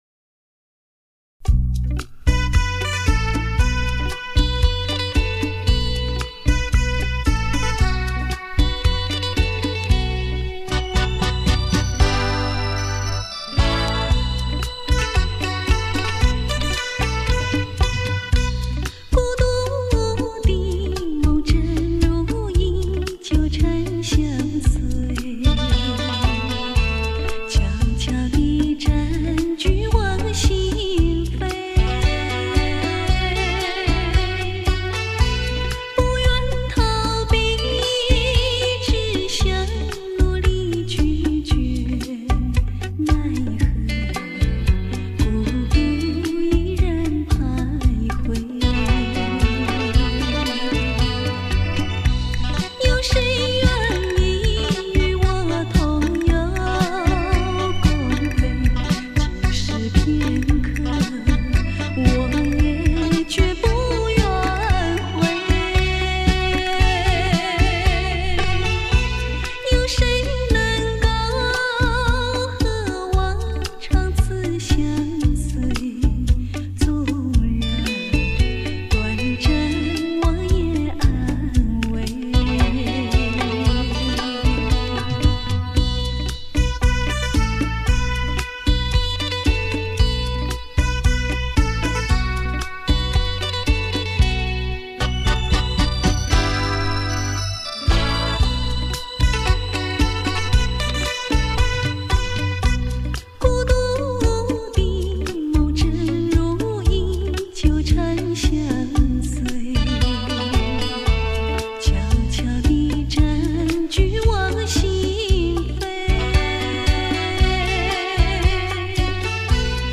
数码采样：松下SL-J85R唱机